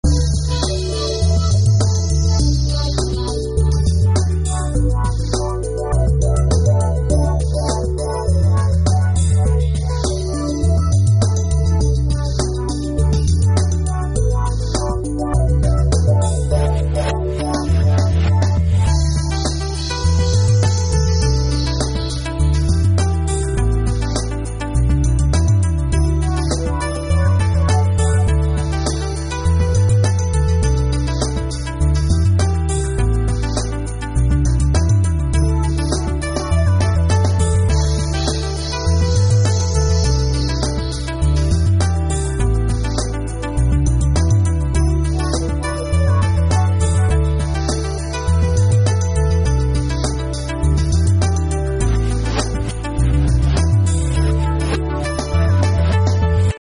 Обожаю музыку chillout)))))